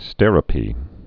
(stĕrə-pē) also As·ter·o·pe (ă-stĕr-)